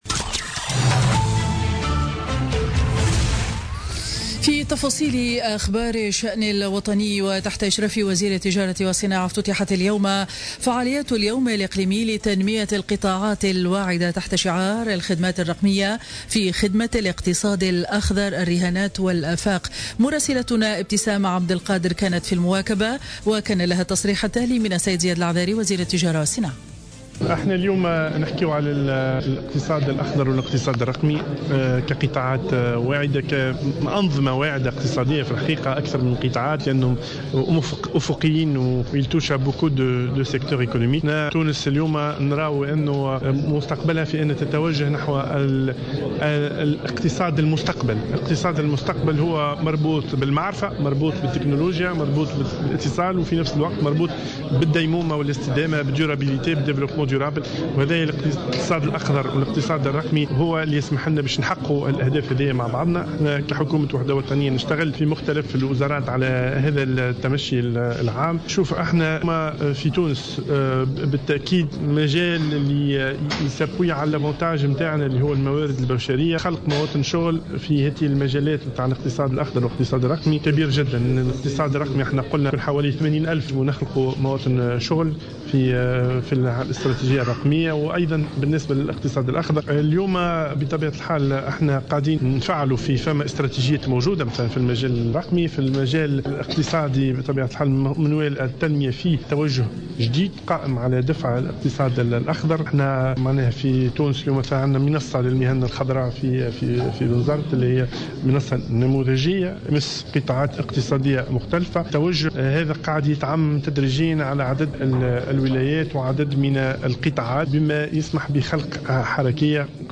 نشرة أخبار منتصف النهار ليوم الاربعاء 22 مارس 2017